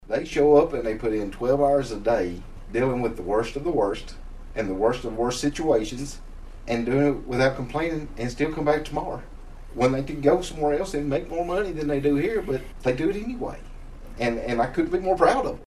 Barren County Jailer, Aaron Shirley took an opportunity to brag on his staff and the work they do.